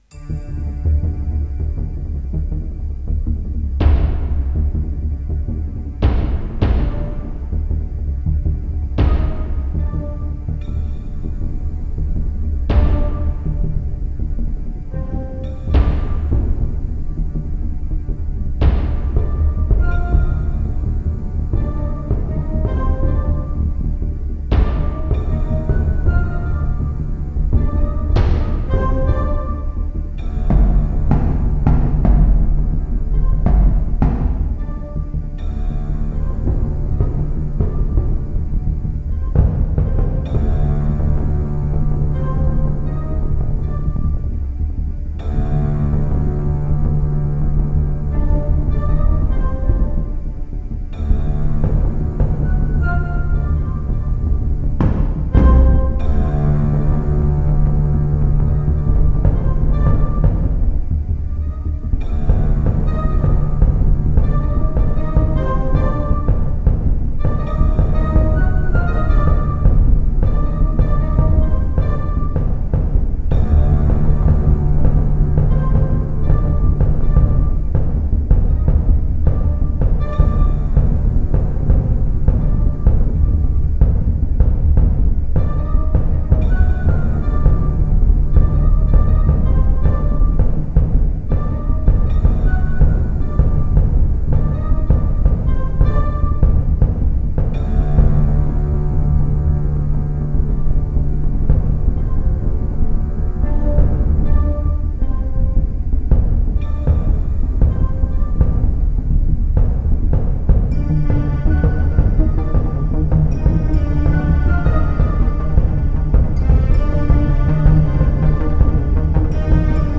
Orchestral Soundtrack with Synths.